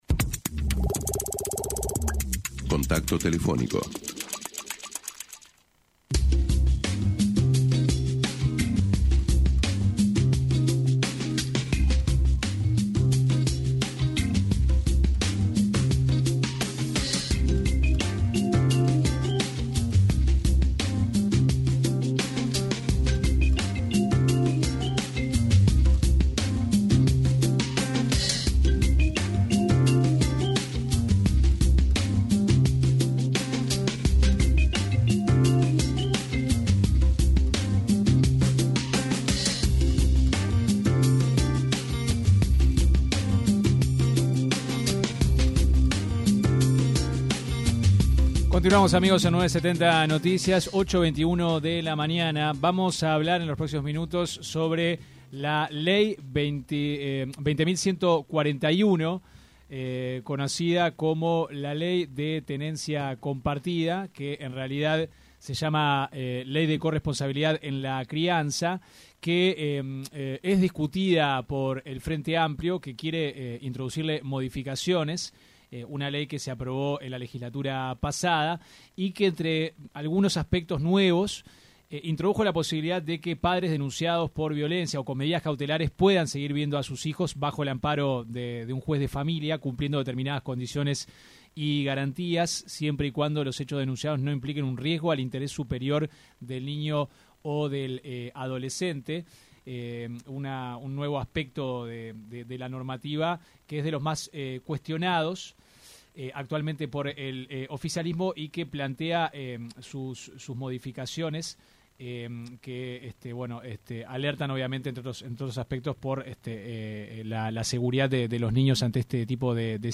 La diputada por el Frente Amplio, Inés Cortés, se refirió en una entrevista con 970 Noticias, a la ley de tenencia compartida, y que buscan introducir algunas modificaciones, concretamente a los artículos 4, 8 y 15 de la ley.